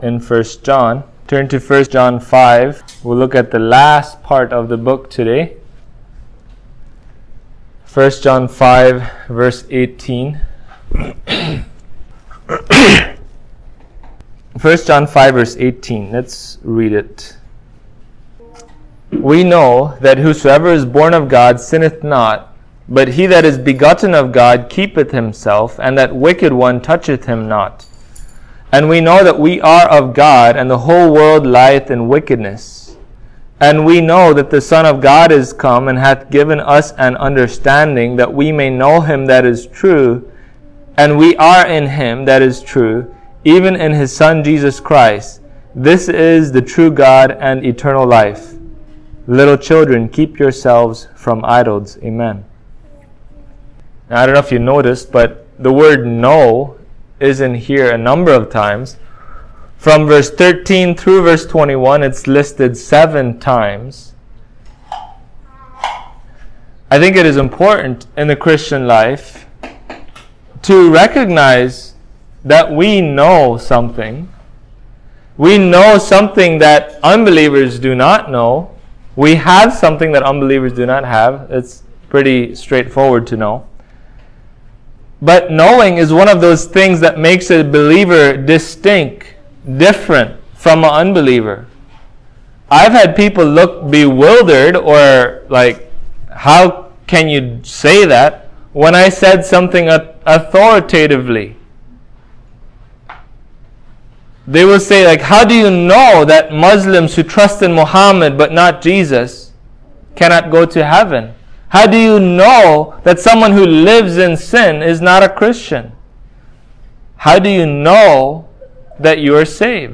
1John 5:18-21 Service Type: Sunday Morning True Believers will be assured by God that their faith is genuine